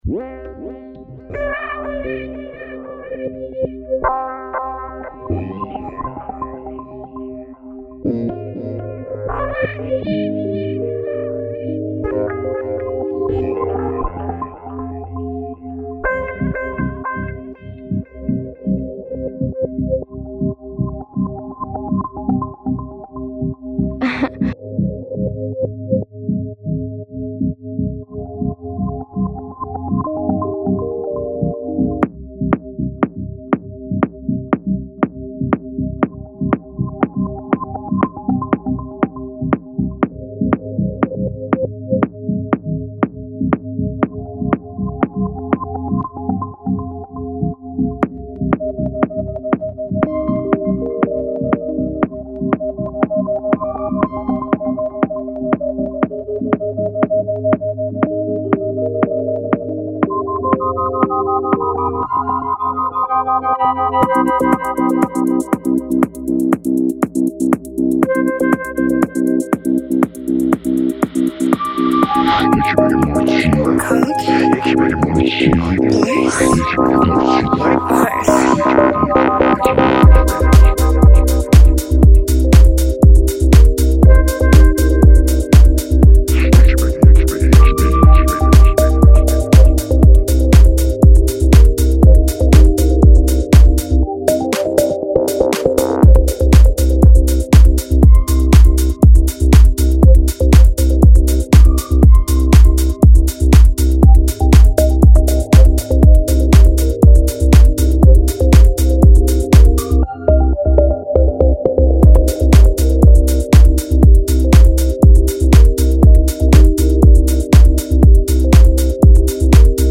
Club Remix